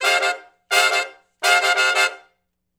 065 Funk Riff (F) har.wav